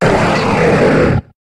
Cri de Drattak dans Pokémon HOME.